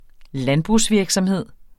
landbrugsvirksomhed substantiv, fælleskøn Bøjning -en, -er, -erne Udtale Betydninger 1.